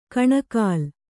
♪ kaṇakāl